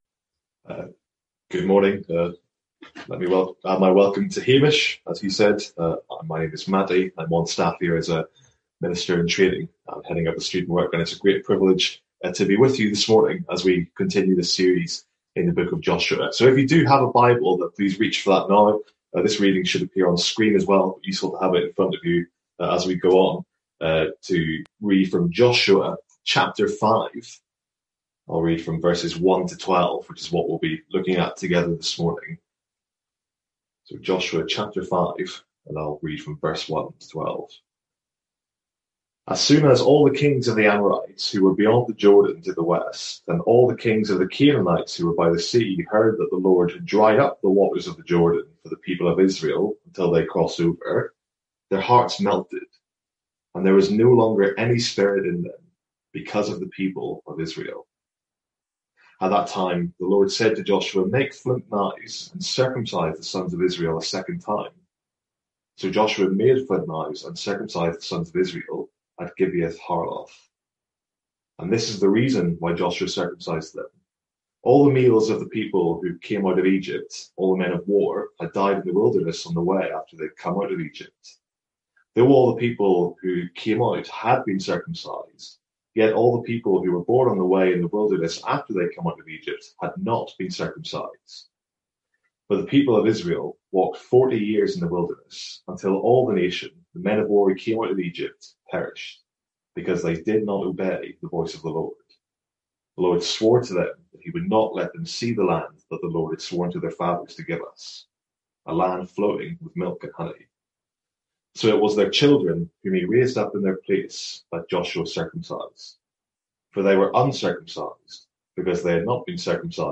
Sermons | St Andrews Free Church
From our morning series in Joshua.